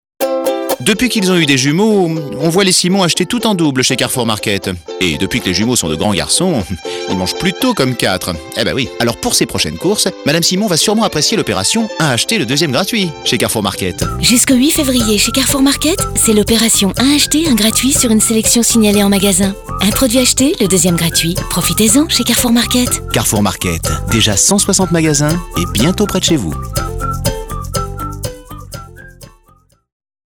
Défi relevé avec cette campagne radio écrite pour Carrefour Market, avec la voix d’Emmanuel Curtil (la voix française de Jim Carrey) et des portraits de clients dans lesquels on sent toute la bienveillance de l’enseigne et son engagement à leur rendre service.